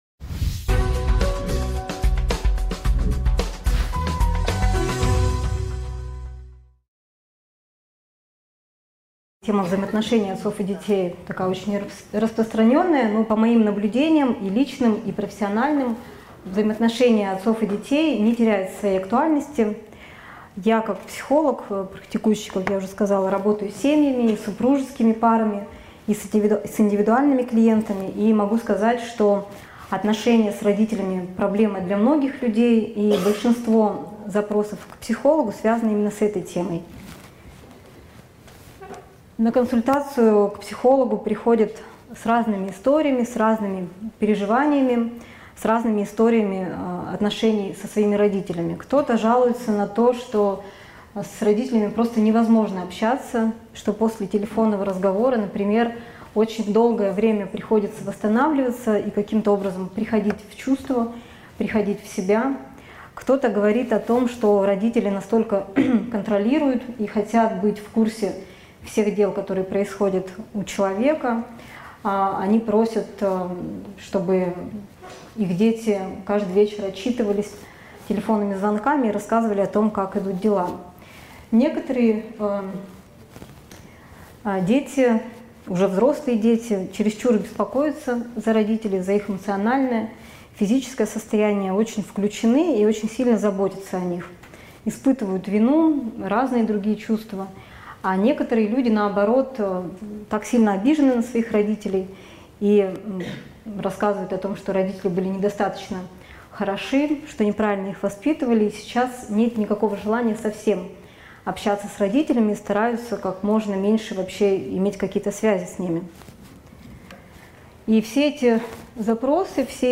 Аудиокнига Нужно ли прерывать отношения с семьей, чтобы стать самостоятельным?